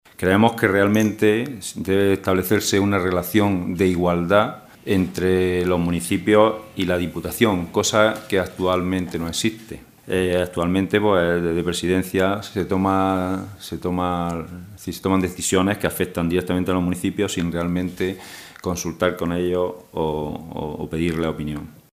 Rueda de prensa que ha ofrecido el Grupo Socialista en la Diputación Provincial de Almería